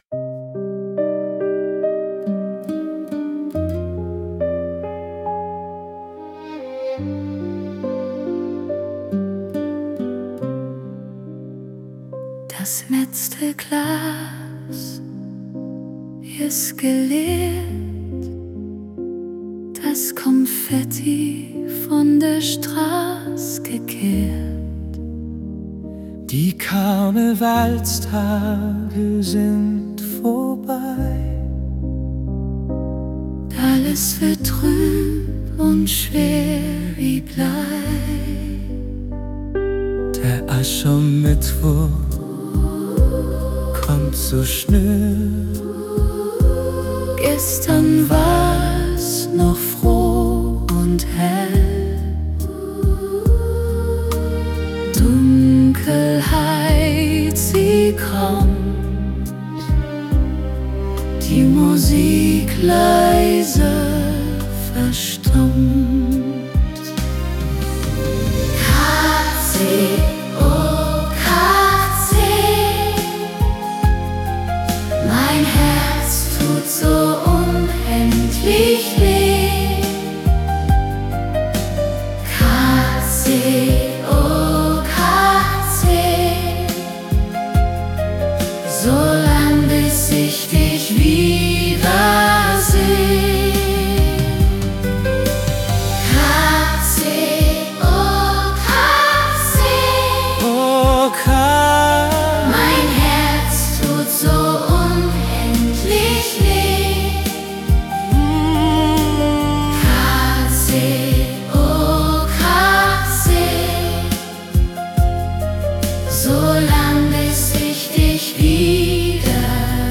Jetzt hab ich die KI zum singen gebracht, aber das andere?